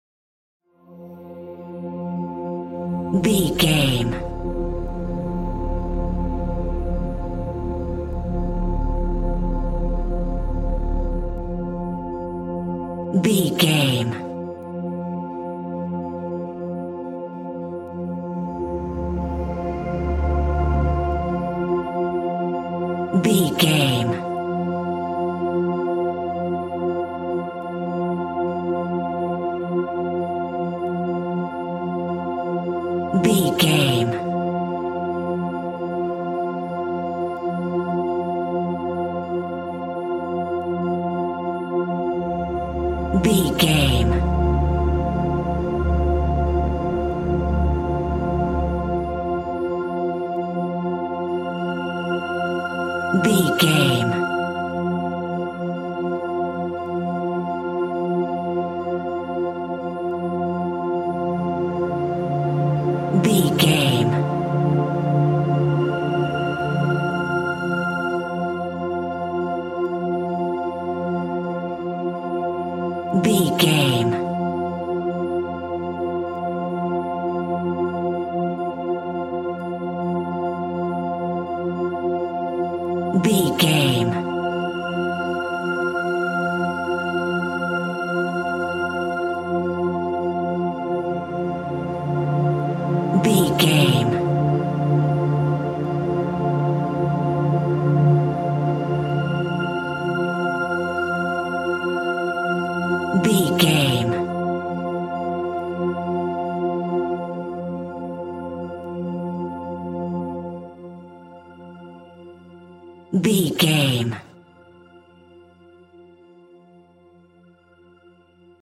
Aeolian/Minor
Slow
ominous
dark
suspense
haunting
eerie
synthesiser
viola
orchestral instruments